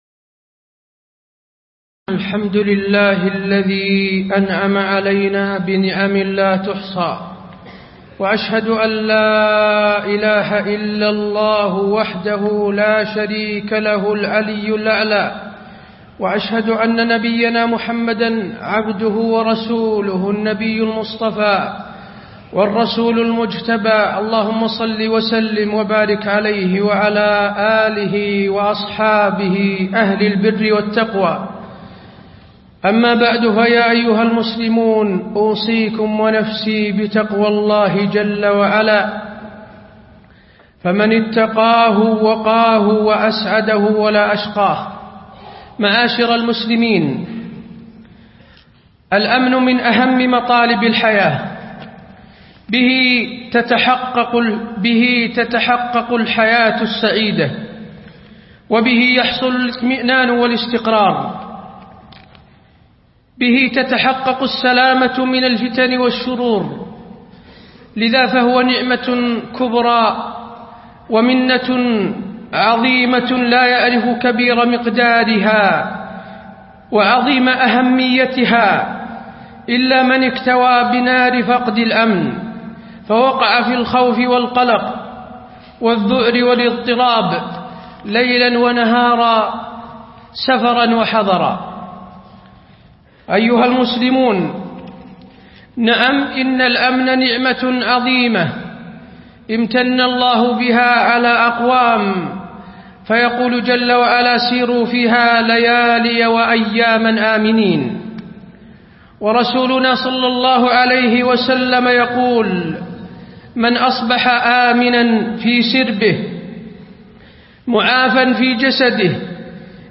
تاريخ النشر ١٩ صفر ١٤٣٣ هـ المكان: المسجد النبوي الشيخ: فضيلة الشيخ د. حسين بن عبدالعزيز آل الشيخ فضيلة الشيخ د. حسين بن عبدالعزيز آل الشيخ نعمة الأمن وخطورة فقده